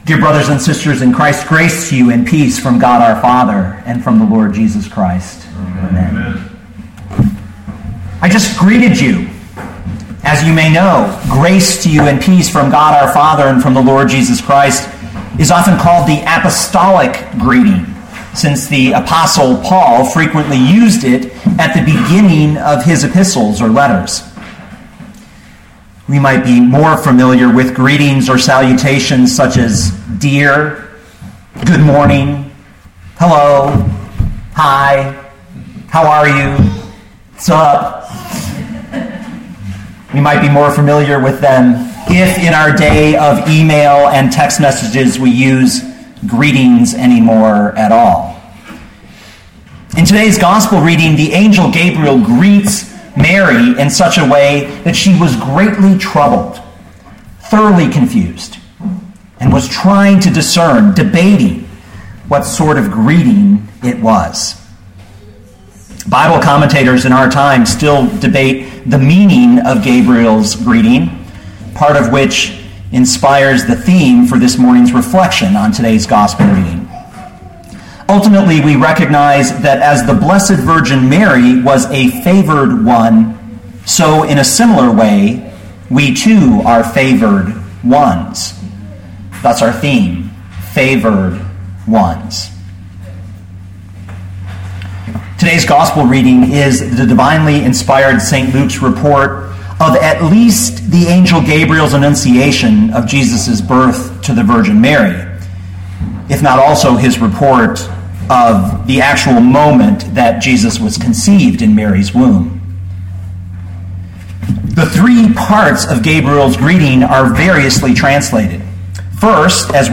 2014 Luke 1:26-38 Listen to the sermon with the player below, or, download the audio.